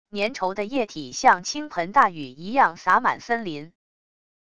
粘稠的液体像倾盆大雨一样洒满森林wav音频